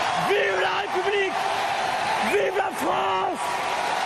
emmanuel_macron_senflamme_et_hurle_pour_son_premie_hRBNoJr.mp3